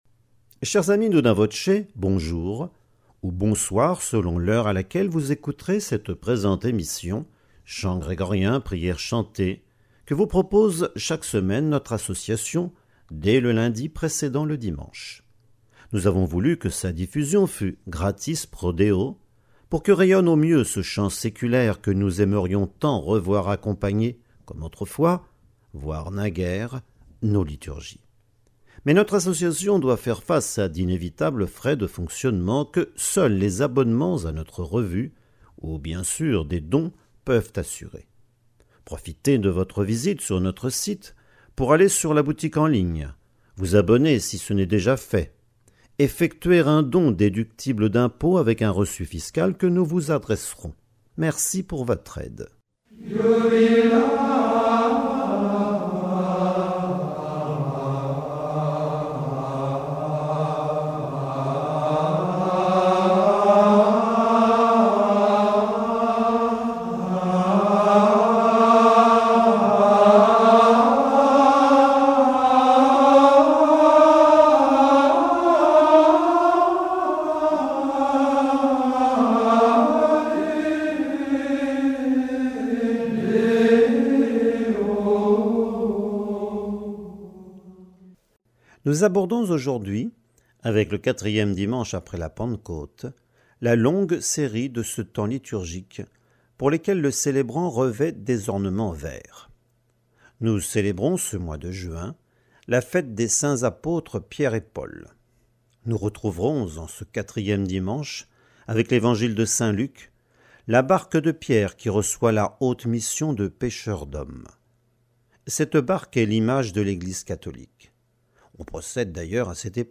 Nous irons à l’abbaye Notre-Dame de Triors pour l’émission avec commentaires.
Chants et commentaires